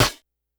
snr_47.wav